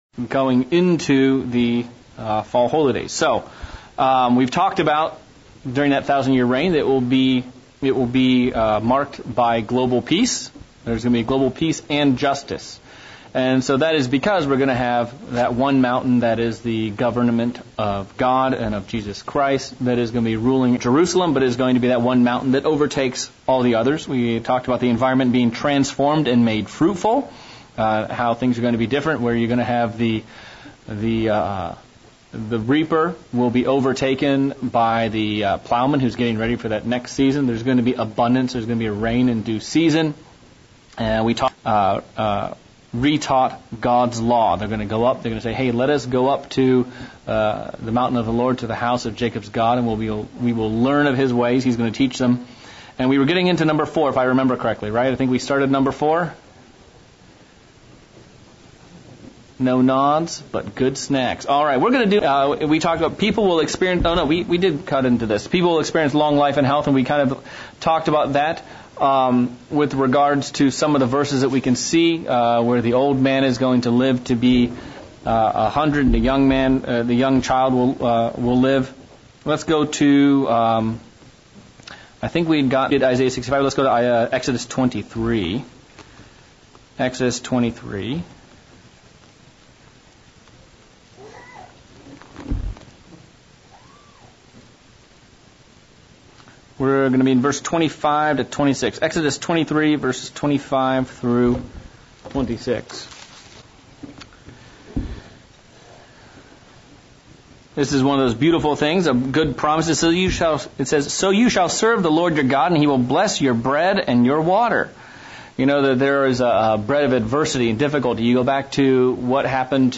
August 2025 Bible Study - Millennium Part 2